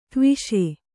♪ tviṣe